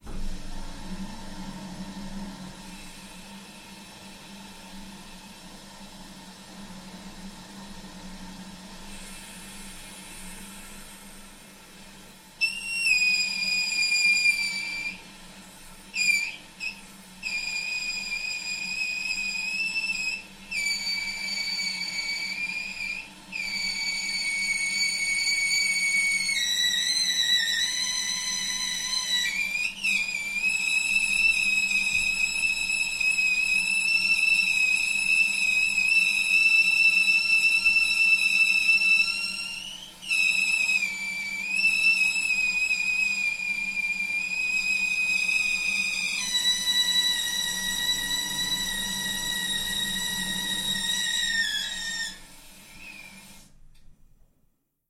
Звуки водопровода
На этой странице собраны разнообразные звуки водопровода: от тихого журчания до резких стуков в трубах.
Звук свистящего крана без подачи воды